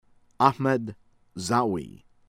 TIZI OUZOU tee-ZEE     oo-ZOO